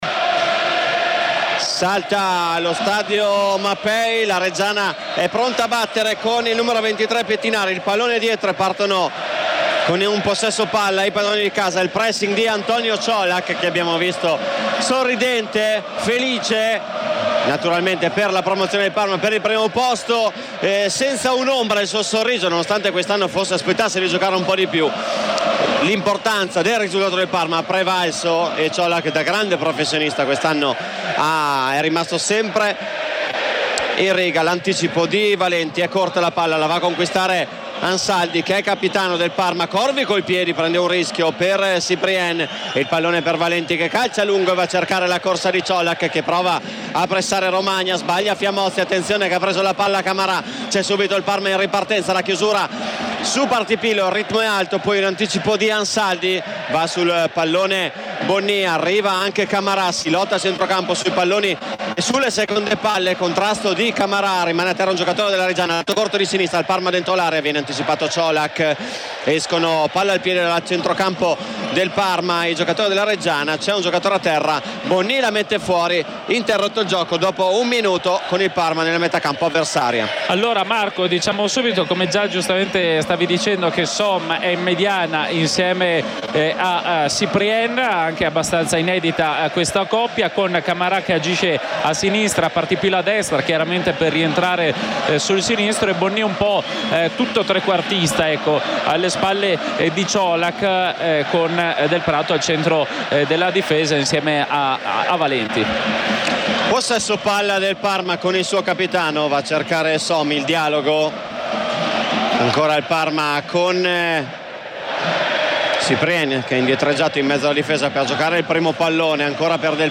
Per il derby Pecchia sceglie Valenti e Colak, conferma Corvi. Radiocronaca
con il commento tecnico